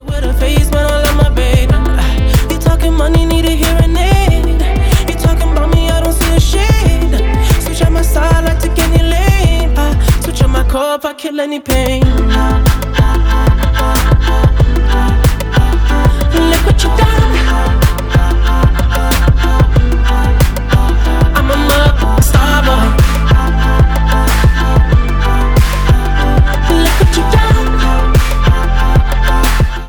• R&B/Soul